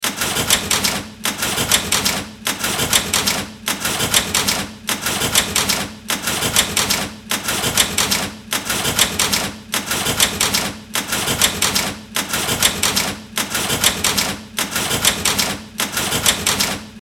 Industrial Rhythmic Machine Sound Effect
Description: Industrial rhythmic machine sound effect. The constant, rhythmic noise created by machinery in an industrial environment. Audio fx loop.
Industrial-rhythmic-machine-sound-effect.mp3